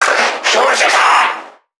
NPC_Creatures_Vocalisations_Infected [50].wav